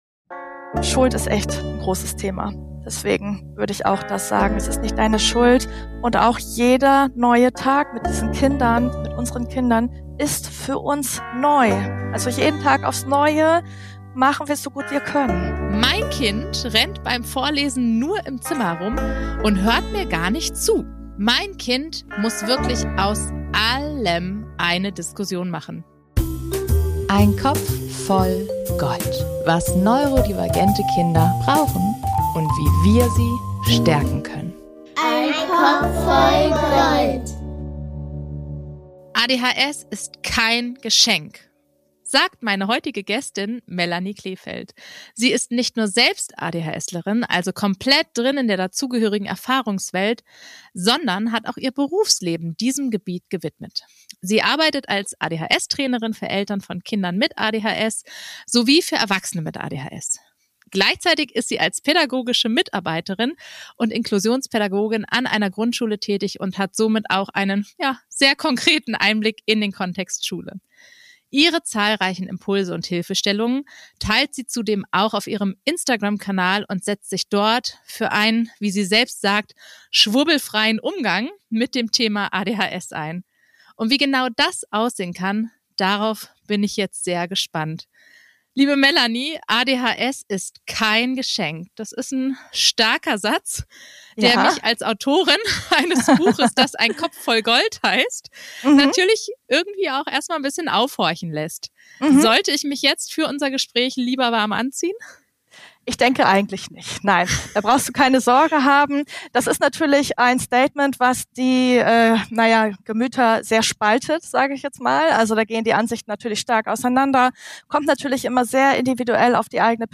In dieser Folge klären wir die Frage, ob ADHS wirklich so eine Superkraft ist, wie uns das die sozialen Medien derzeit gerne erklären wollen. Wir schauen auf die tatsächlichen Stärken aber auch auf die realen Herausforderungen – in Schule wie im Familienalltag. Dazu gibt es jede Menge konkrete Impulse und ein bisschen Nähkästchenplauderei.